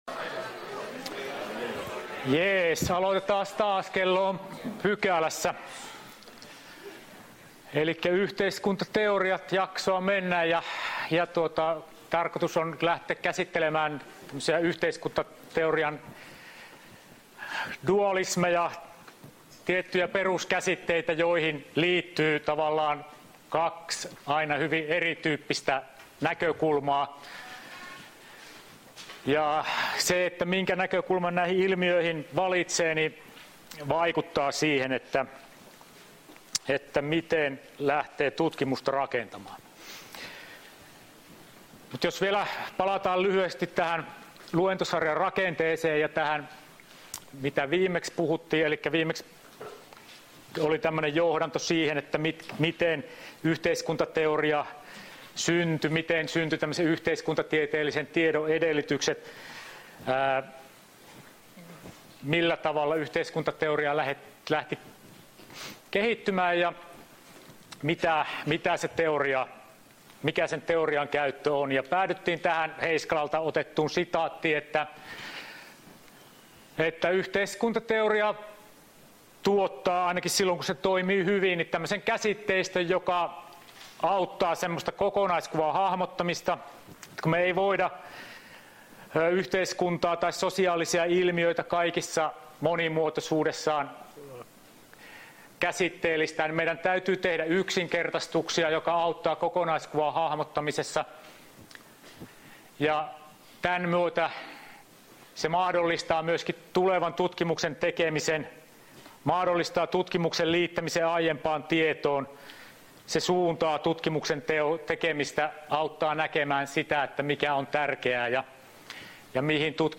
Luento 31.10.2018